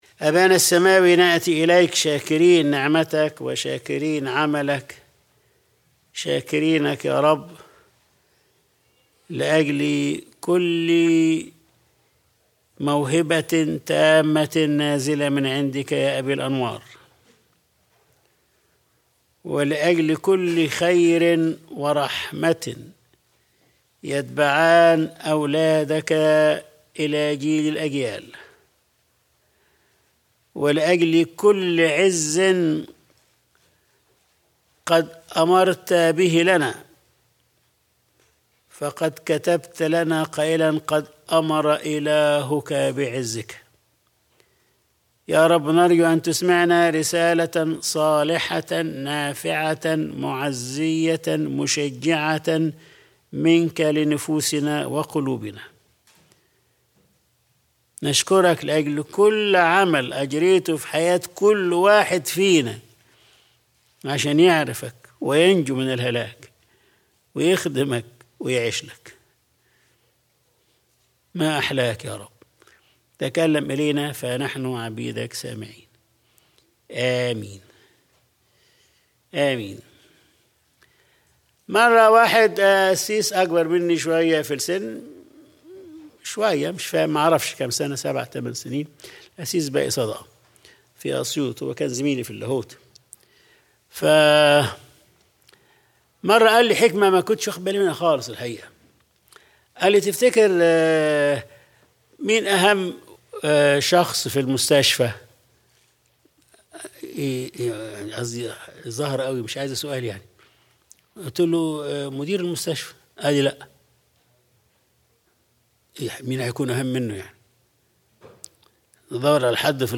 Sunday Service | صُلحًا .. صُلحًا .. وهكذا